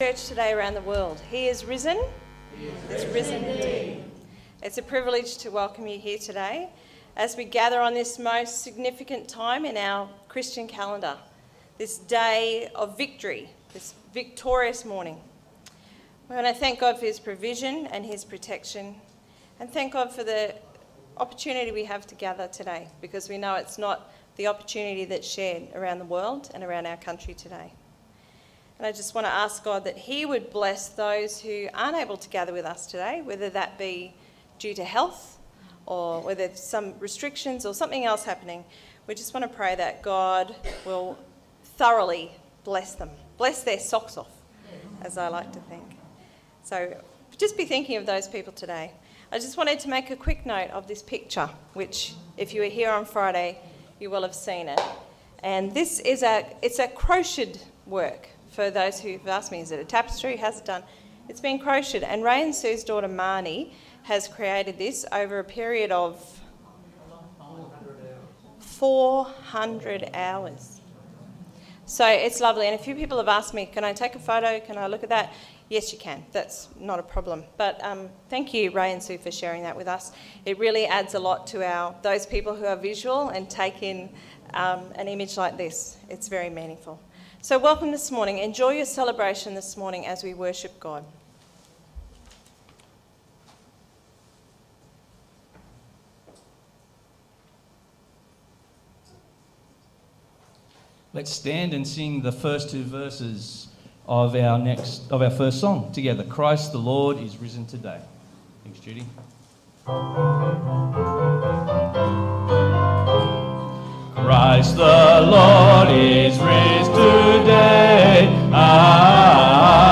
Easter_Sunday_4th_April_2021_Audio.mp3